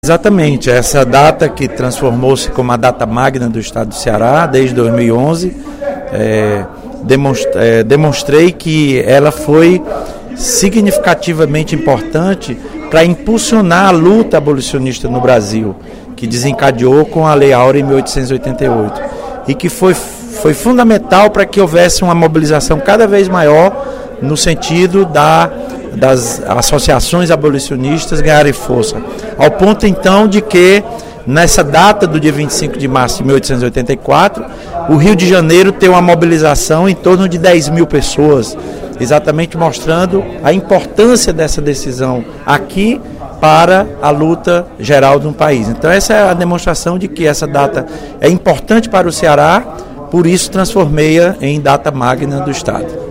O deputado Lula Morais (PCdoB) justificou, durante o primeiro expediente da sessão plenária desta quinta-feira (07/03), a importância histórica do dia 25 de março para o Ceará.